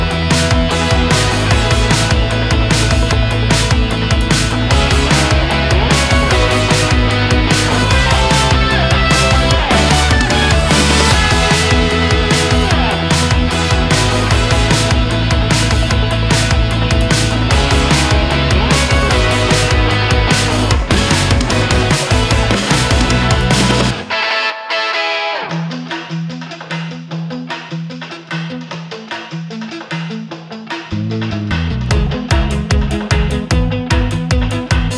K-Pop Pop Soundtrack
Жанр: Поп музыка / Соундтрэки